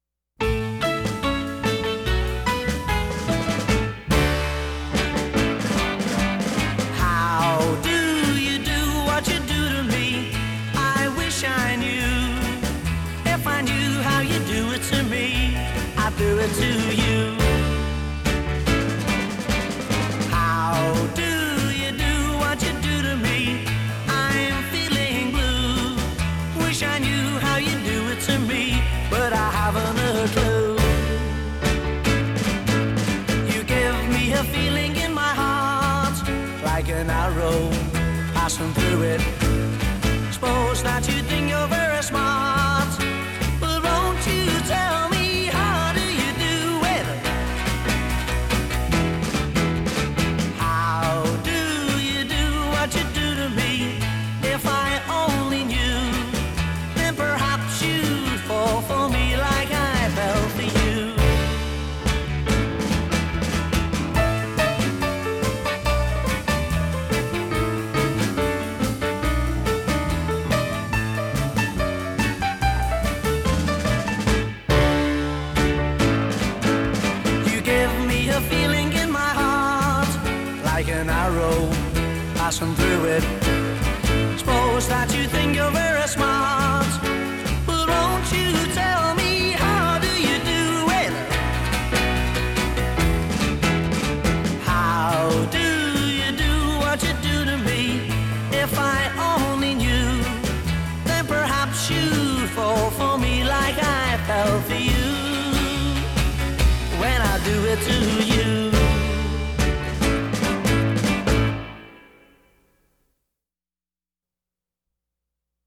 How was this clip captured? This is the stereo version.